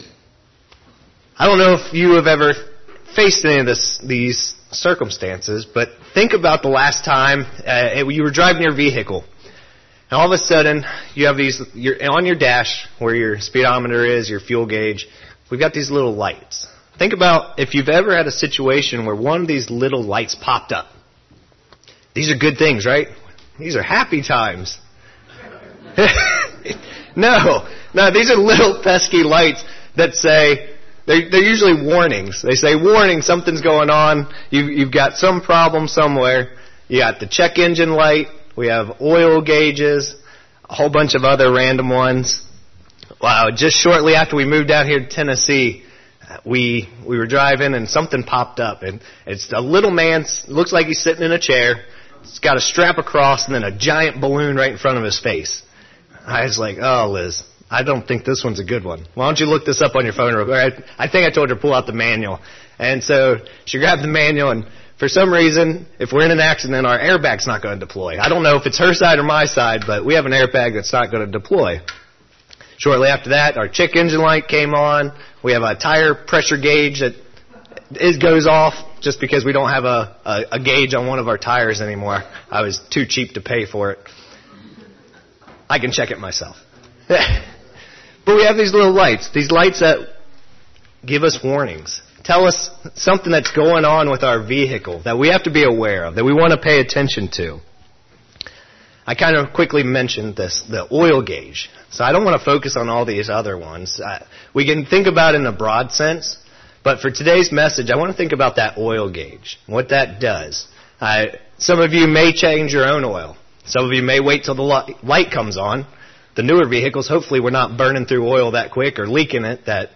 Sermons
Given in Murfreesboro, TN Nashville, TN